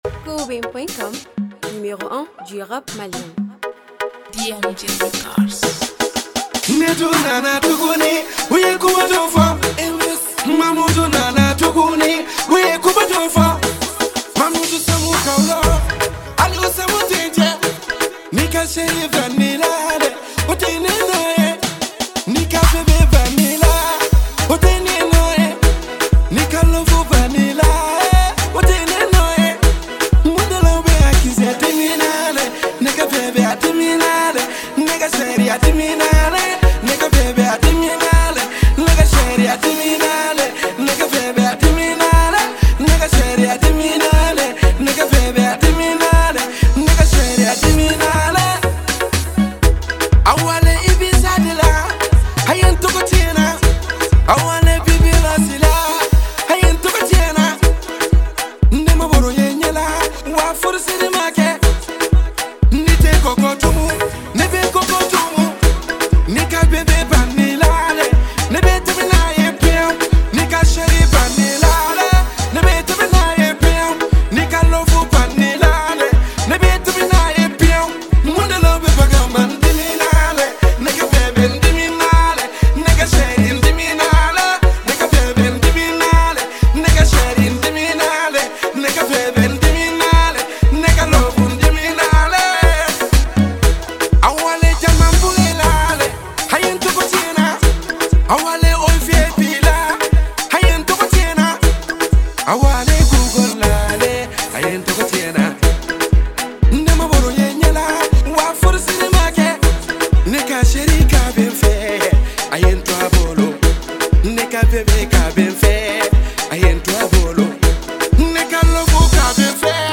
musique Mali world.